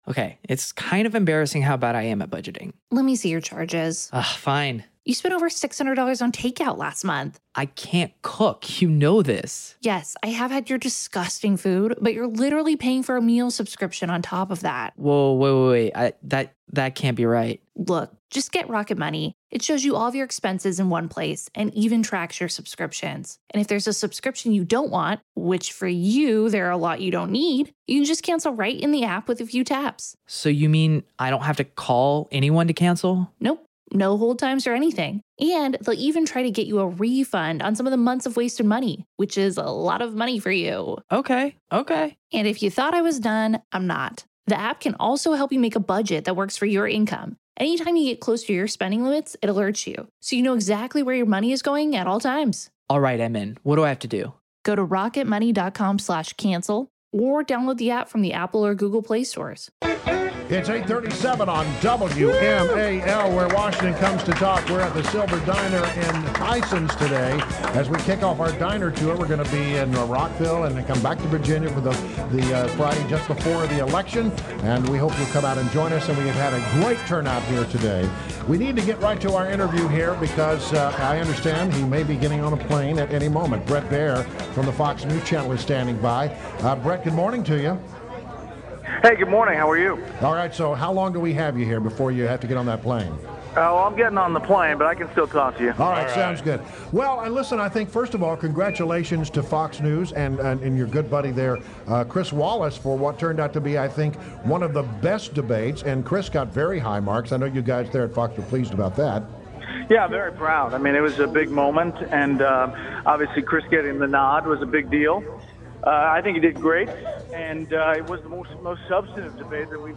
WMAL Interview - BRET BAIER - 10.21.16
INTERVIEW – BRET BAIER – Anchor of Special Report weekdays at 6 pm and on Sundays at 8 pm on Fox News Channel – discussed his thoughts on debate and Chris Wallace moderation.